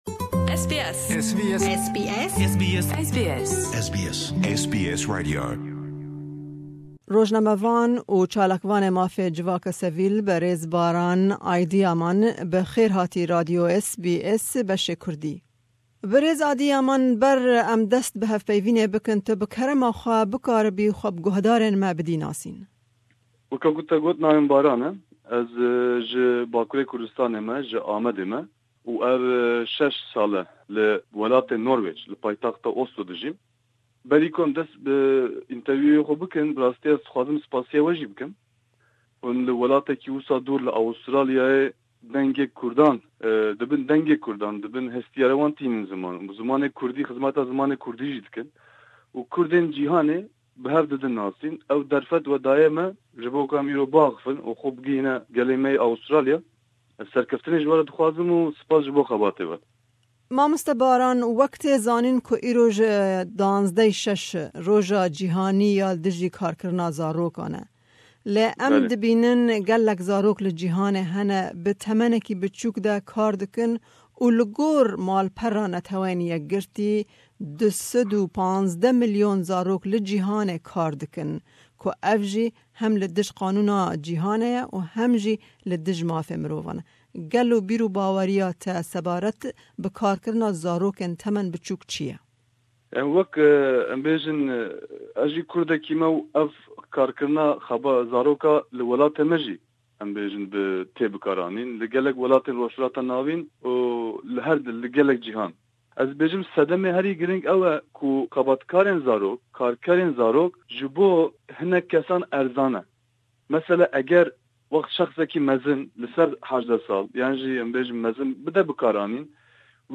Îroj, 12/6/2016 roja Cîhanî ya dijî Karkirina Zarokan e. Me derbarî wê babetê hevpeyvînek